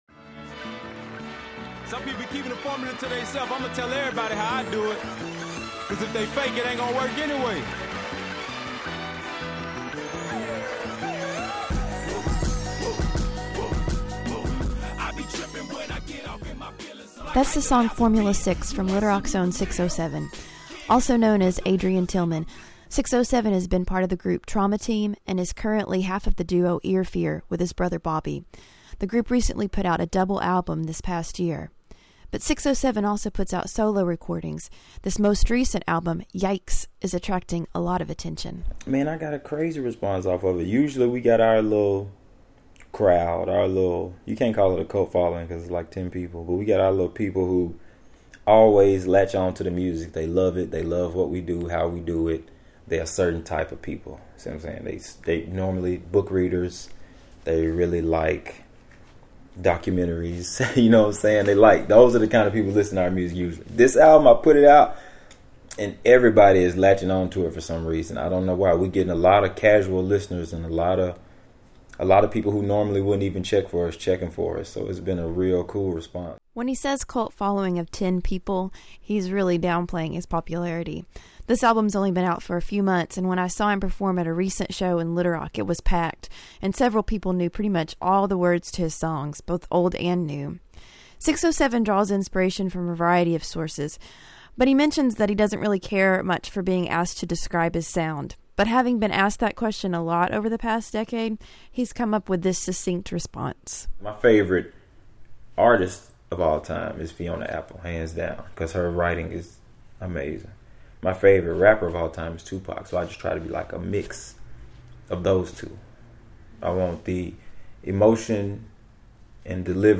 Be advised, his music contains language that may be offensive to some.